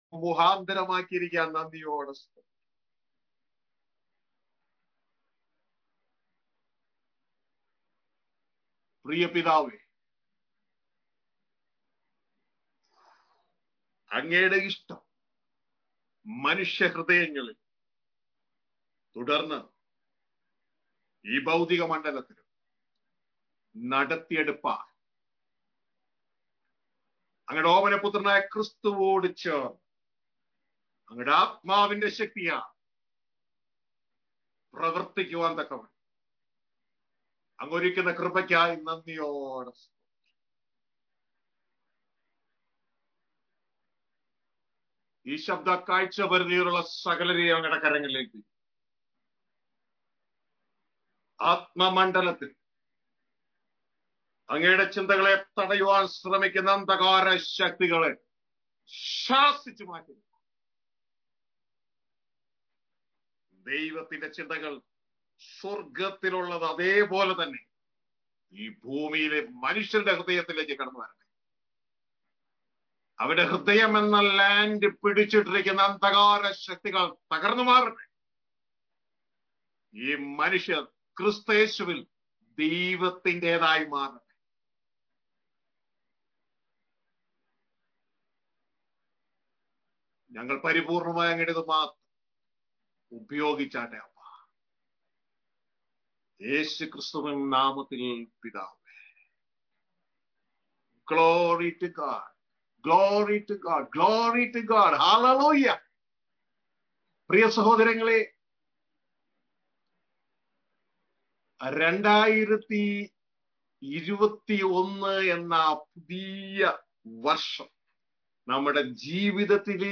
New year message 2021 The years of Gear-up.mp3